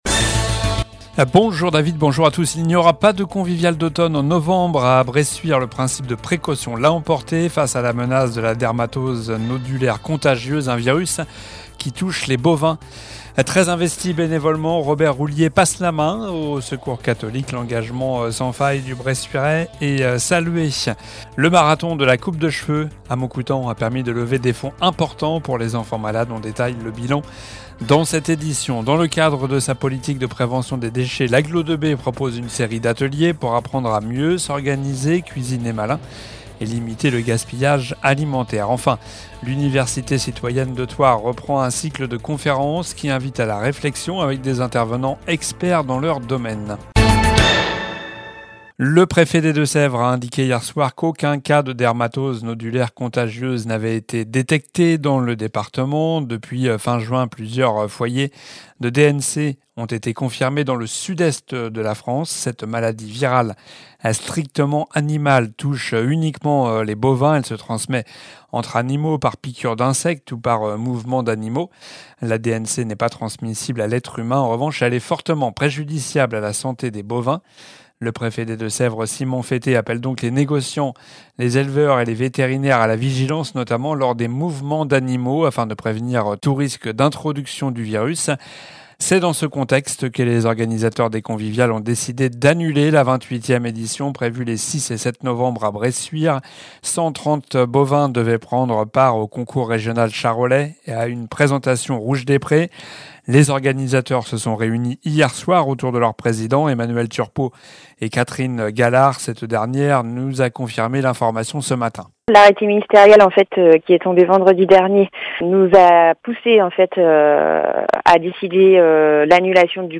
Journal du mardi 21 octobre (midi)